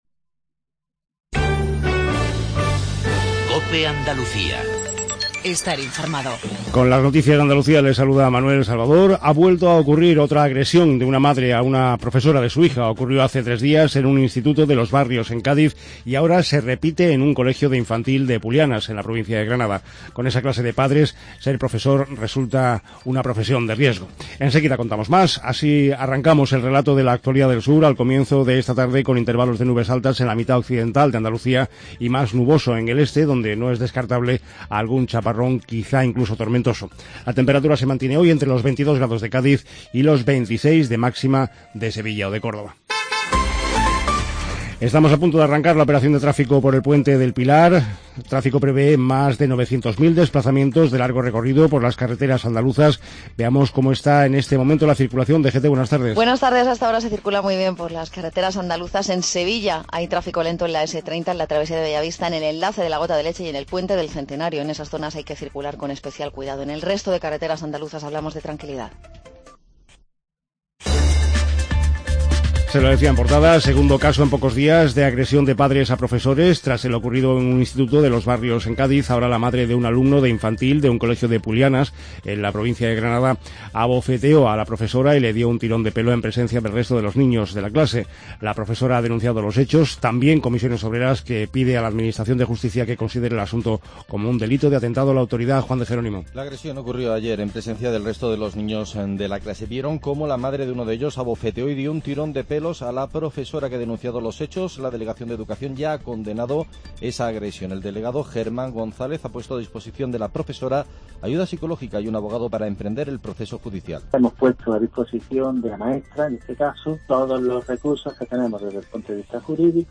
INFORMATIVO REGIONAL/LOCAL MEDIODIA